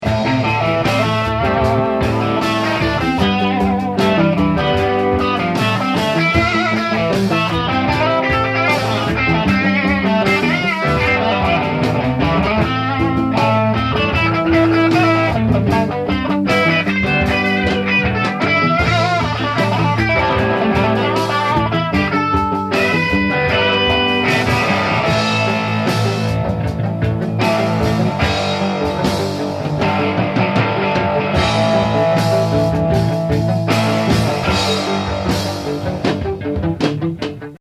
Probelokal 1982